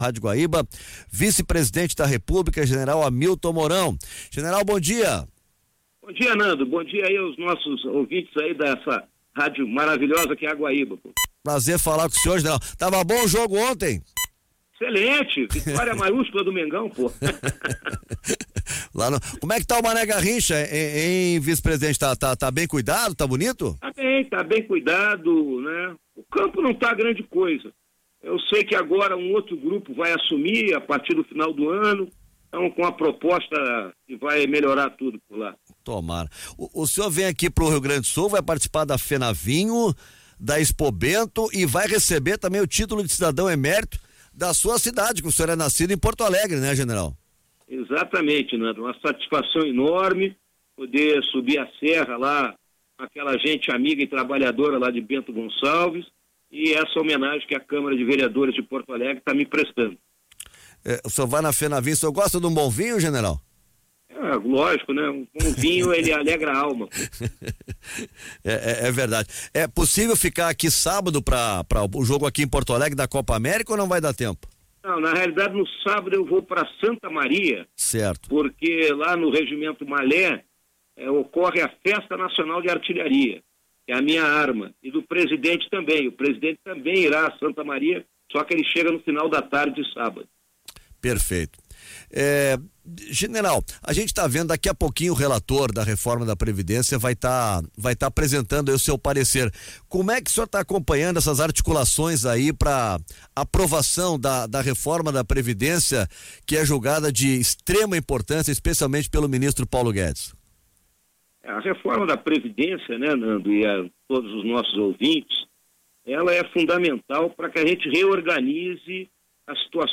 Vice-presidente concedeu entrevista exclusiva à Rádio Guaíba nesta quinta (13)
Ouça a íntegra da entrevista de Hamilton Mourão: